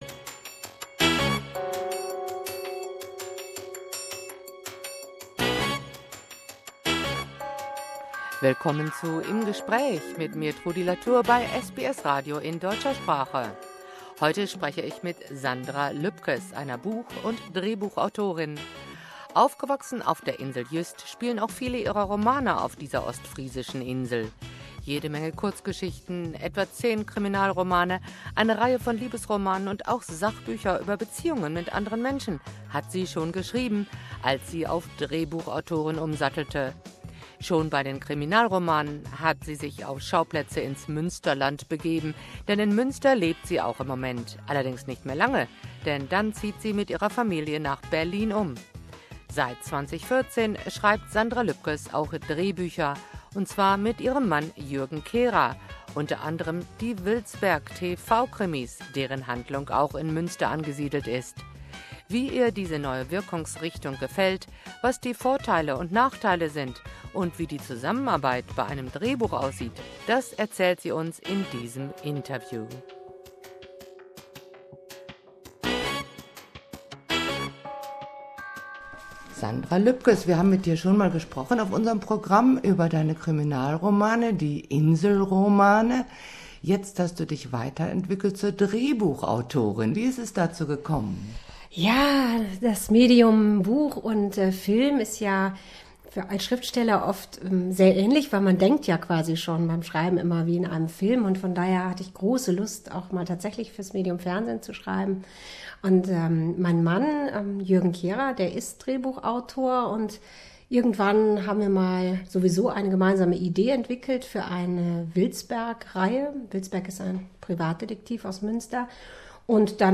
Wie ihr die neue Wirkungsrichtung gefällt, was die Vorteile und Nachteile der Genres für den Autor sind, und wie die Zusammenarbeit bei einem Drehbuch aussieht, das erzählt sie uns in diesem Interview.